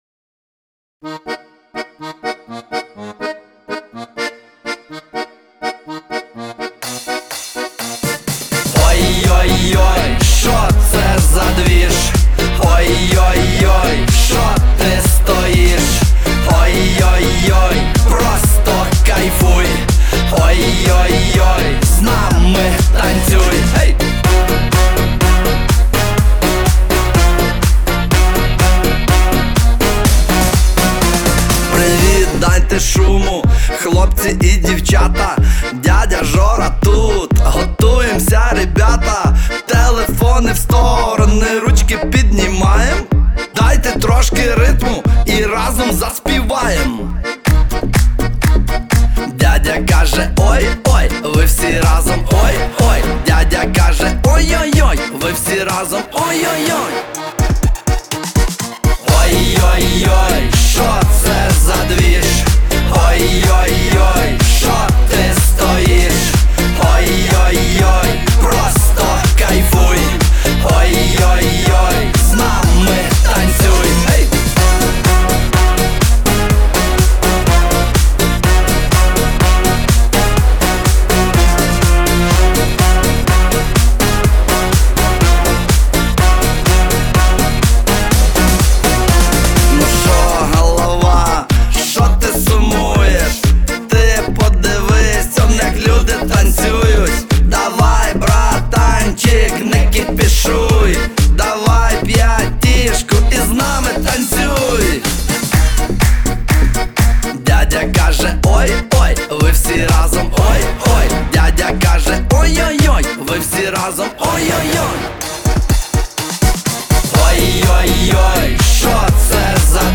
Трек размещён в разделе Русские песни / Шансон.